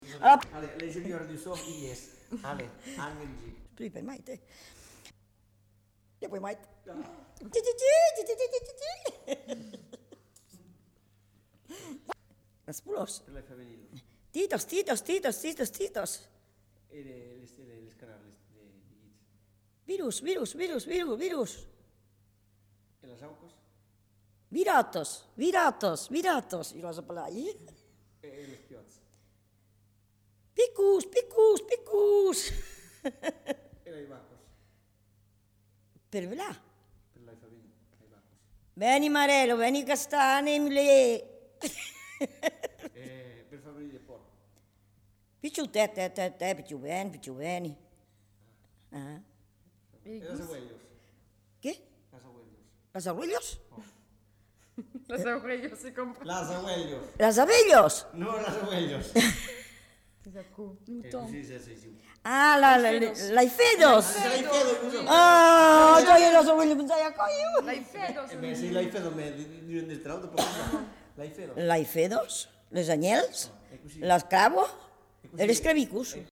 Appels du bétail
Aire culturelle : Lauragais
Lieu : Lanta
Genre : expression vocale
Effectif : 1
Type de voix : voix de femme
Production du son : crié
Classification : appel au bétail